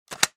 На этой странице вы найдете подборку звуков, связанных с работой фонариков: щелчки кнопок, гудение светодиодов, шум переключателей.